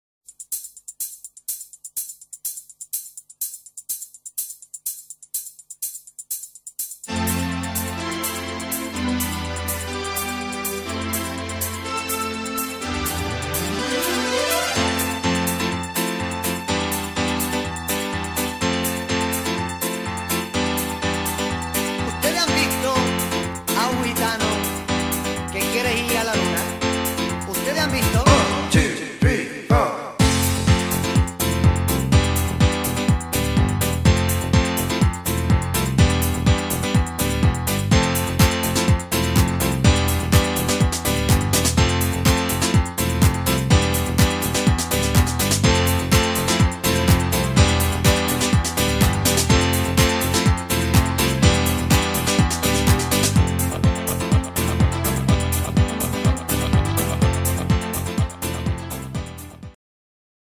music from an 80s rave